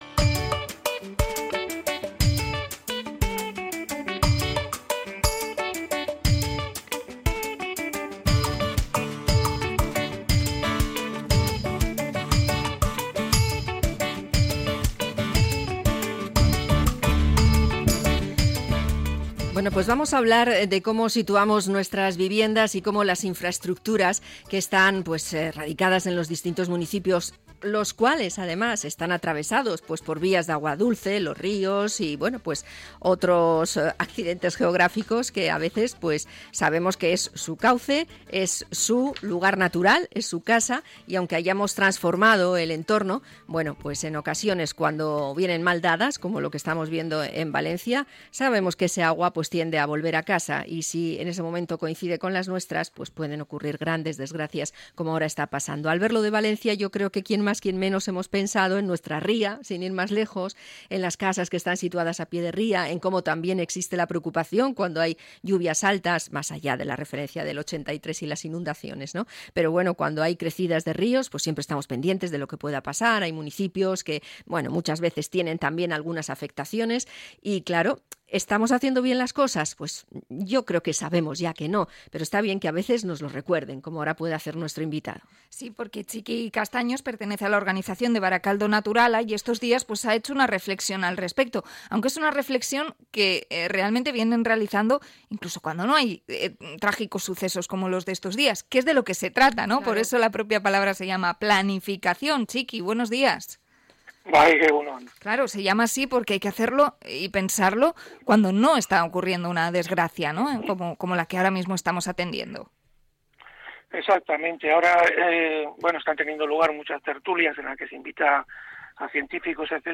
Entrevista a Barakaldo Naturala sobre las zonas inundables